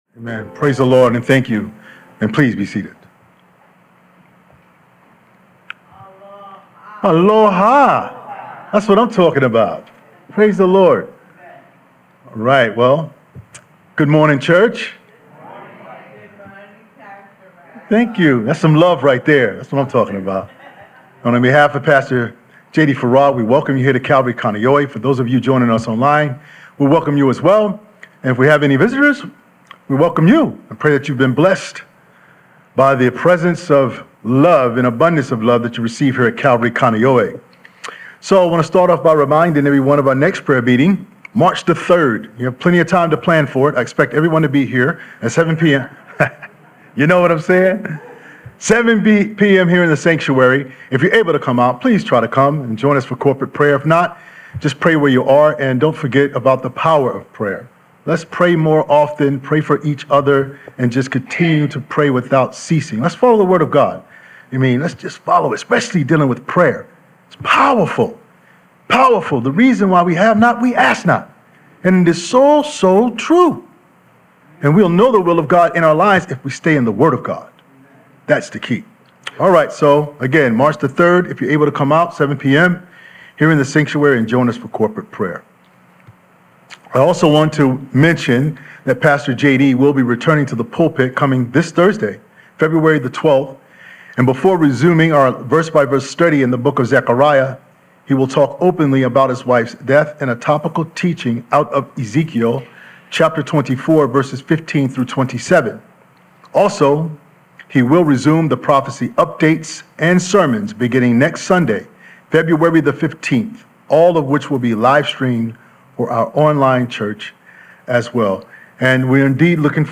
Sunday 2nd Service,“The Law of Faith Pt II”– Feb 8th, 2026